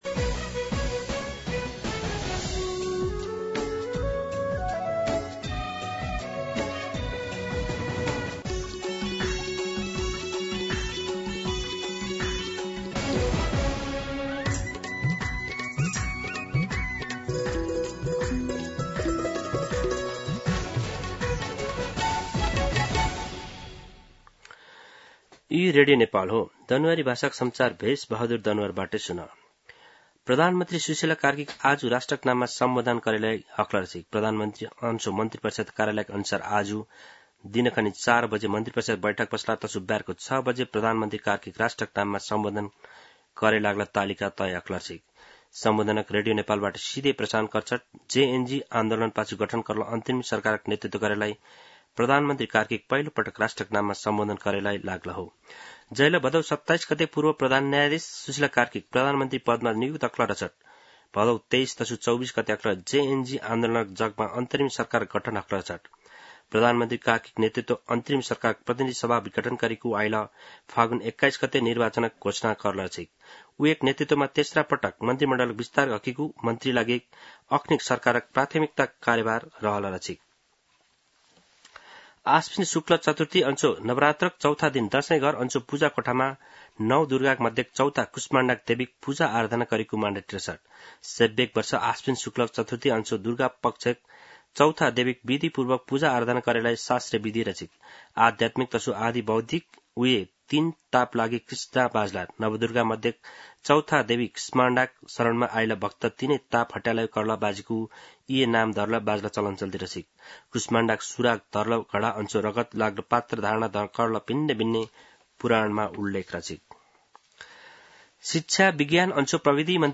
दनुवार भाषामा समाचार : ९ असोज , २०८२